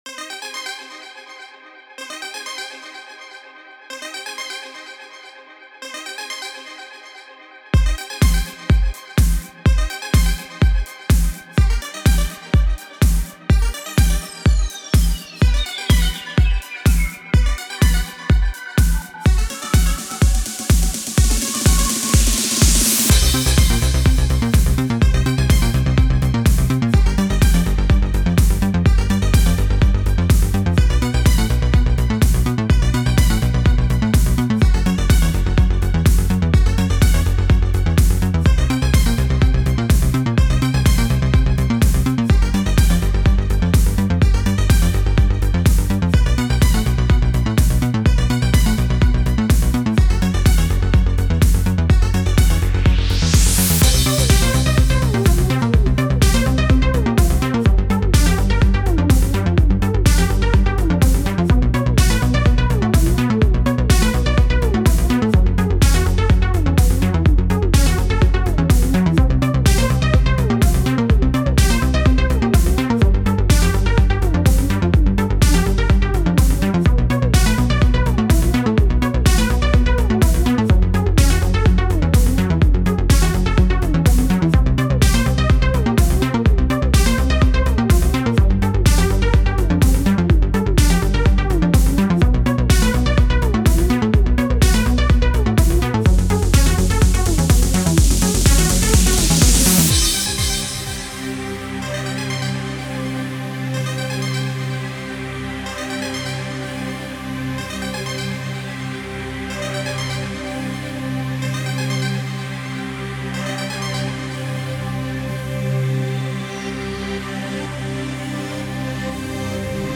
Sci Fi, 80's, maybe you find it useful for your projects :)